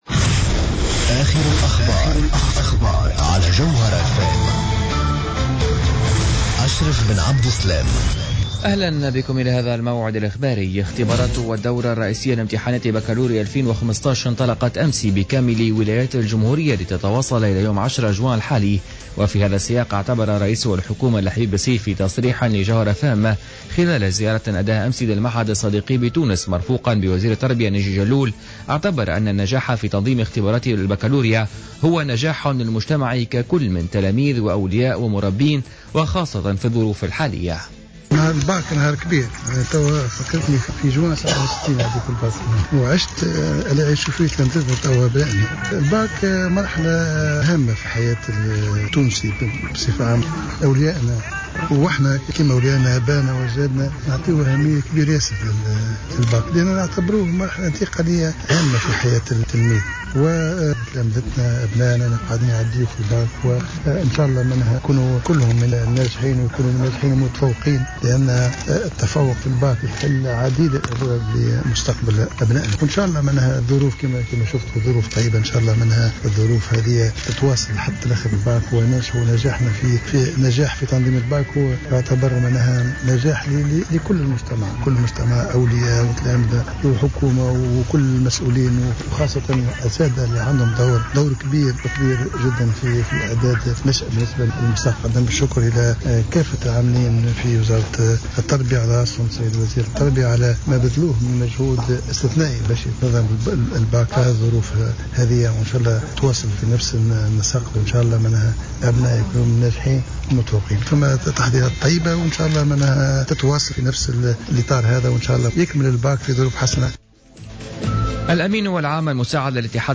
نشرة أخبار منتصف الليل ليوم الخميس 04 جوان 2015